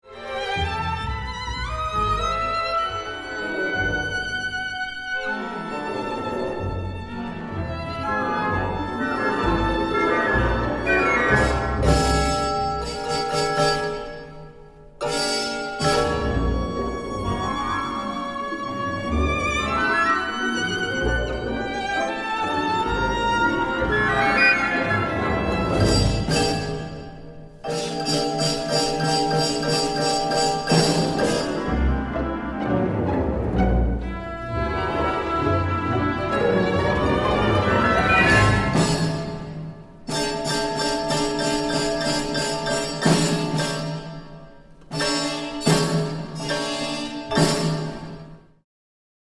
Audio excerpts from the world premiere